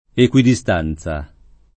ekUidiSt#nZa] (s. f.)